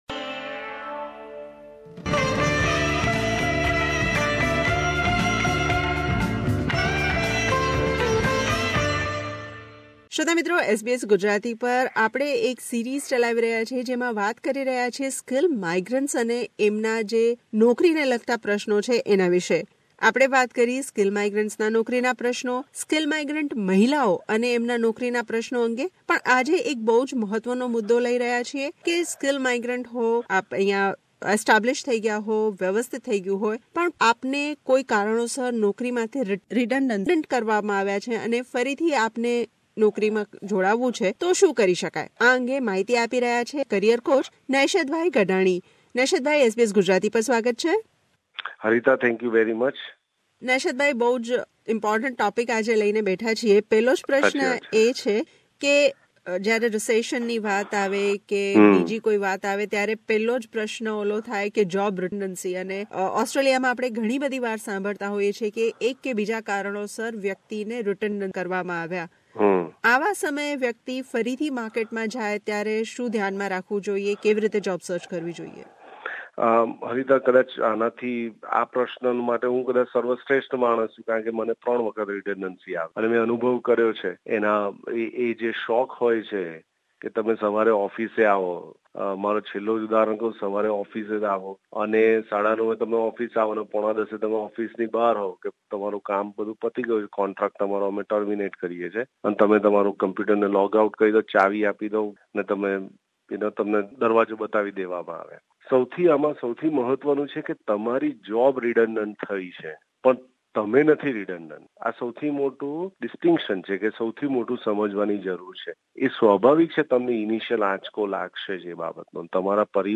મુલાકાત